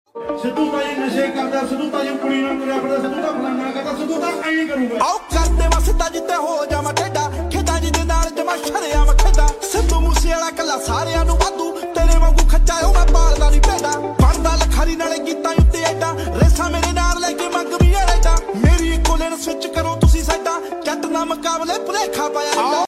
Chery Tiggo pro Front sound effects free download
Chery Tiggo pro Front and rear dash, 24/7 recording with hardwire